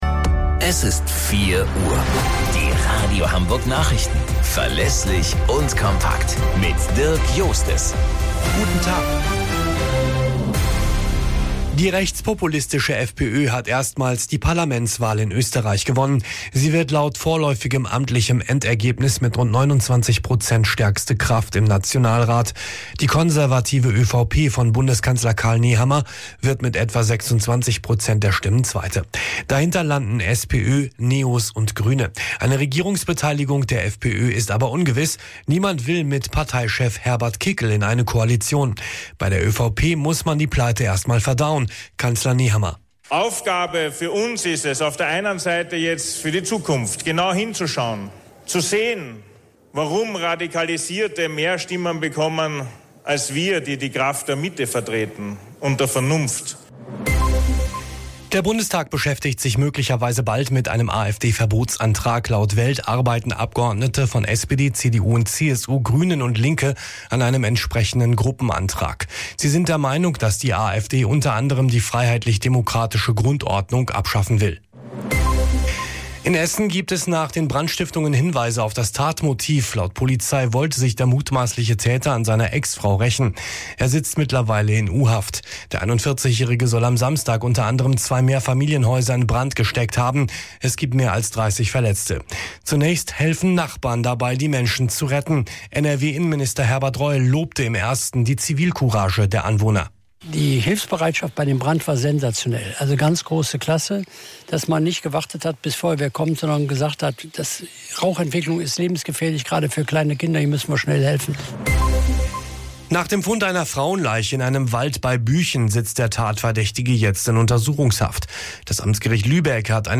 Radio Hamburg Nachrichten vom 30.09.2024 um 04 Uhr - 30.09.2024